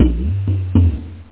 DRUM5.mp3